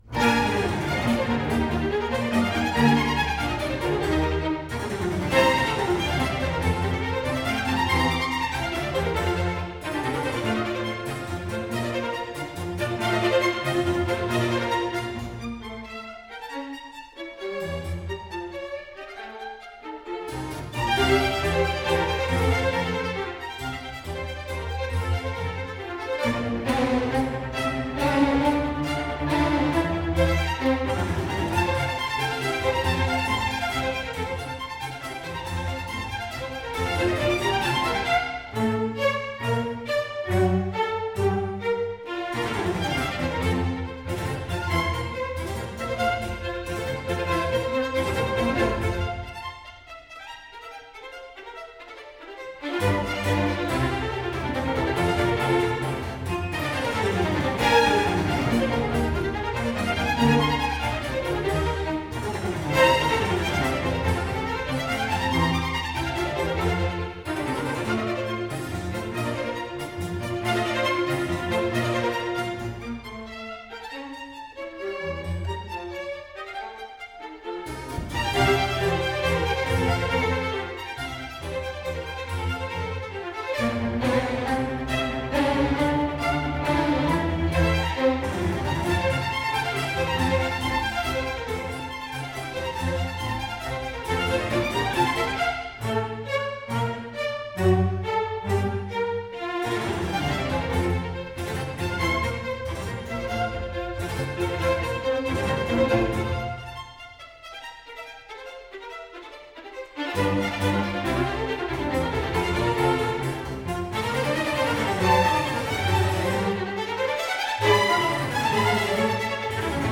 Carl Philipp Emanuel Bach: III. Presto. From “Sinfonia no. 2 in B flat major”.
Camerata Bern